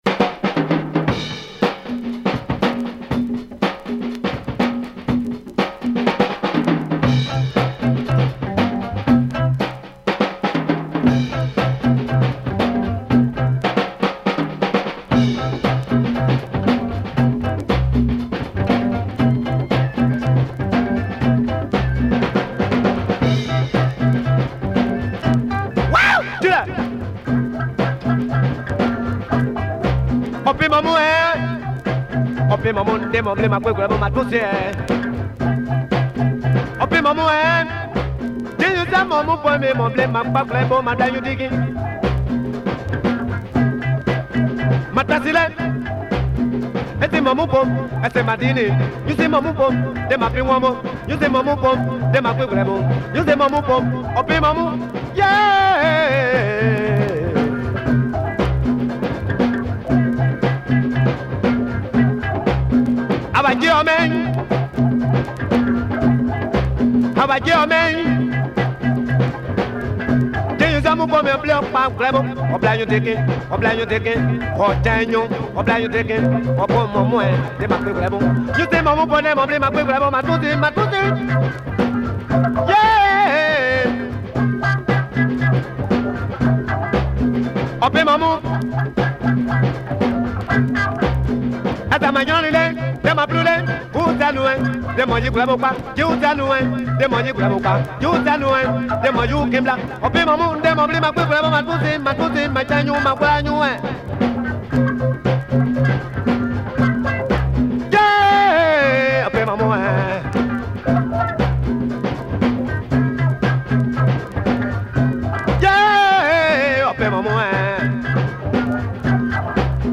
with its breakbeat intro